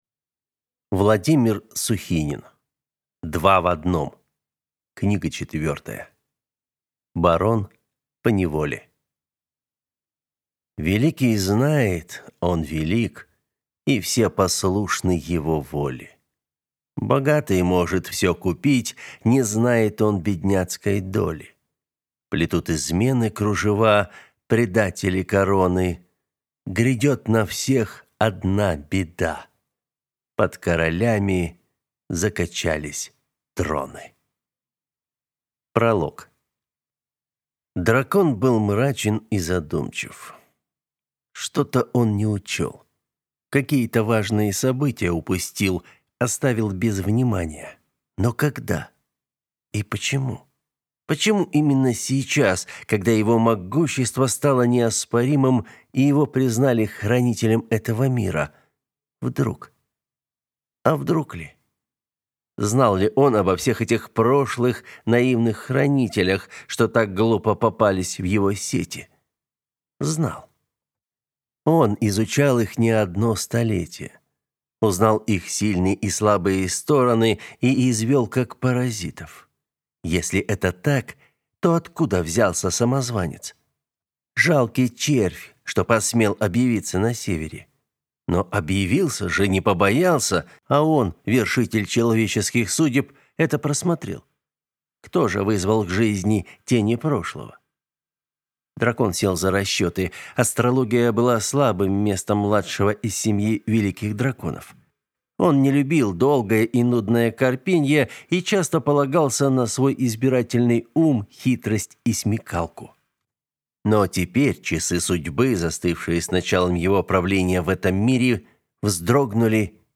Аудиокнига Два в одном. Барон поневоле | Библиотека аудиокниг